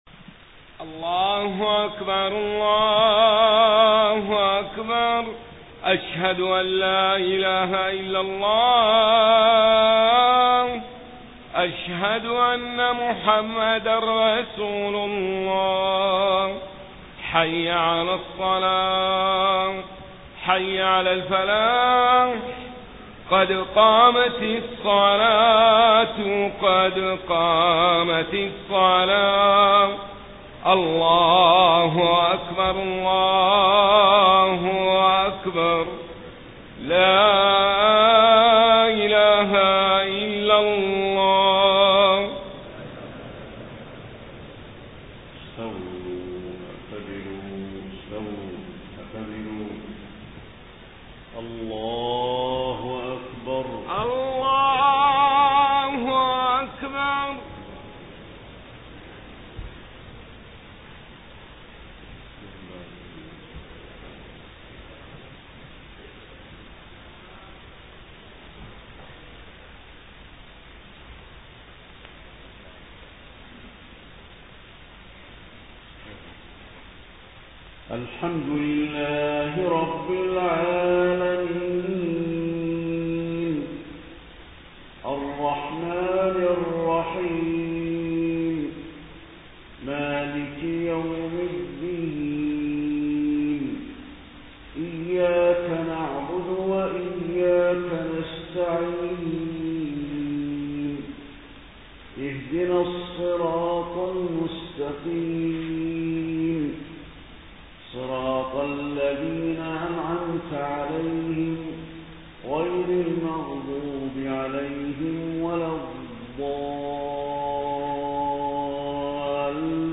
صلاة العشاء 16 ربيع الأول 1431هـ سورة النبأ كاملة > 1431 🕌 > الفروض - تلاوات الحرمين